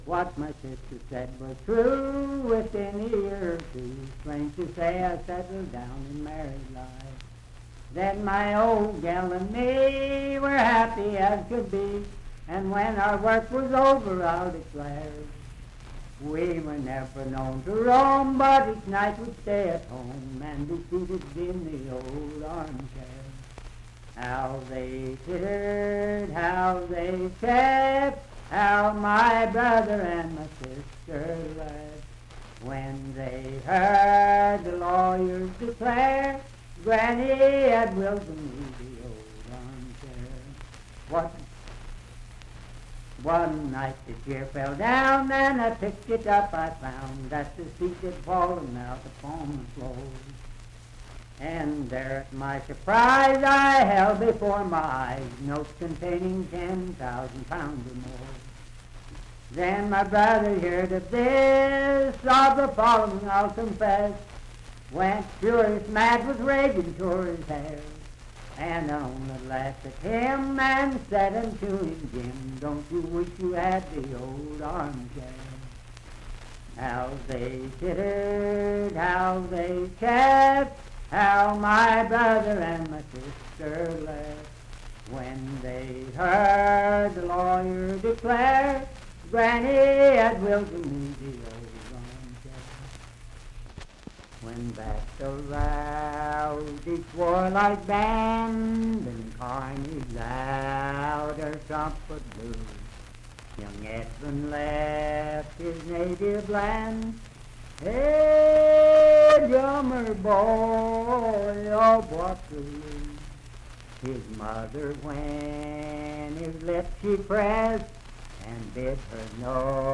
Unaccompanied vocal music and folktales
Verse-refrain 5(4).
Voice (sung)
Wood County (W. Va.), Parkersburg (W. Va.)